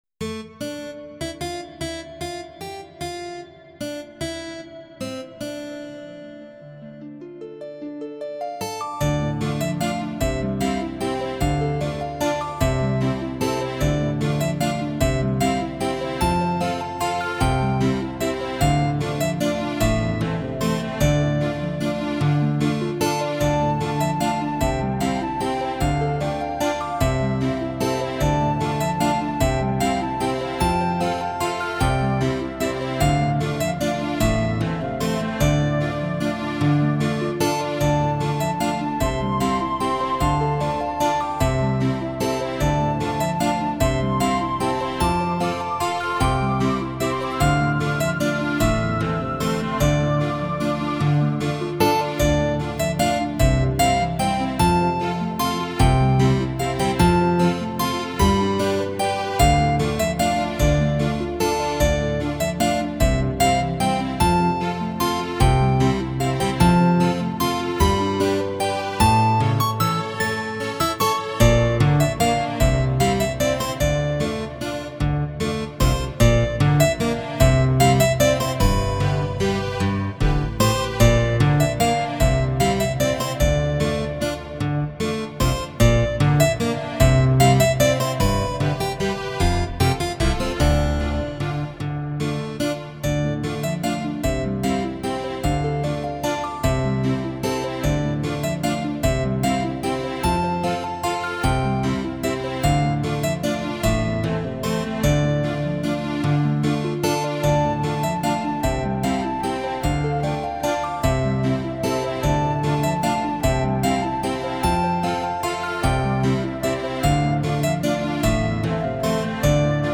Its a MIDI-Track using the ChaosSoundfont I changed a bit.
The song should create a middleage like atmosphere using some basic instruments.
Impressive stuff! I like that clear crisp sound Smile
nice tune though, very spiritual Smile
Nice guitar/sitar sound.
Oh yeah....that sounds nice...I love that kind of Knight, middle-age music.
great sound quality, and something a bit different. i like it.